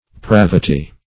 Search Result for " pravity" : The Collaborative International Dictionary of English v.0.48: Pravity \Prav"i*ty\, n. [L. pravitas, from pravus crooked, perverse.]